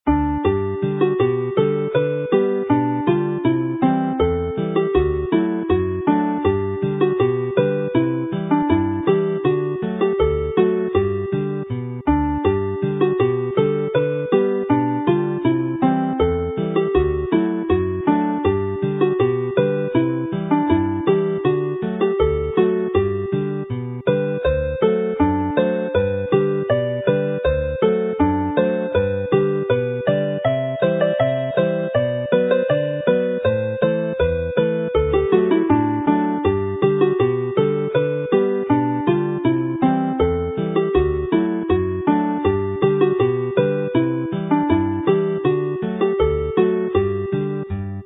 The dance associated with this tune repeats part B in a 48-bar rather than the usual 32-bar structure in this set.